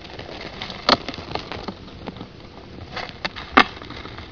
Il existe un monde sonore animal inconnu à nos oreilles, que des microphones hyper-sensibles peuvent révéler.
vers.mp3